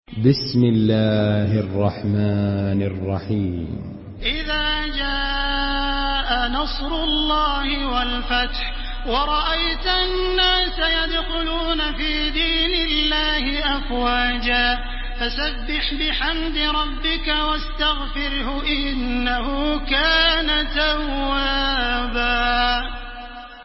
Surah আন-নাসর MP3 by Makkah Taraweeh 1430 in Hafs An Asim narration.
Murattal Hafs An Asim